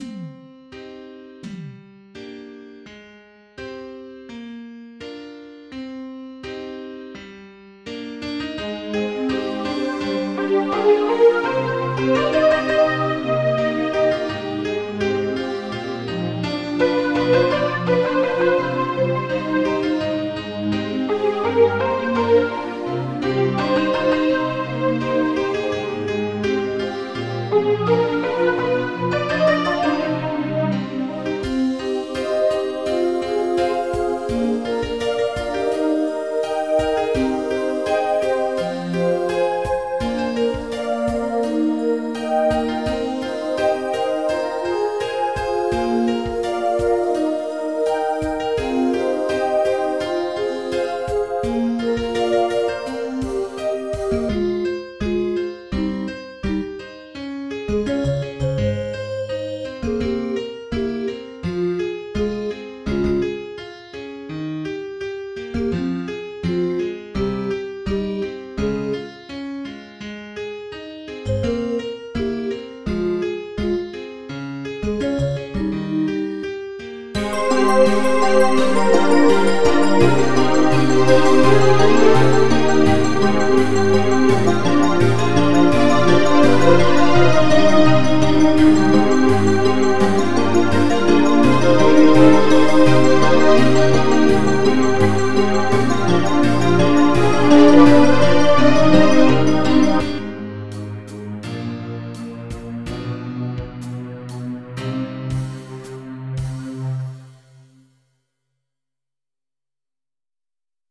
インストバージョン